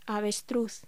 Locución: Avestruz